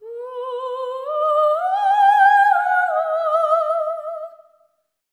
LEGATO 07 -R.wav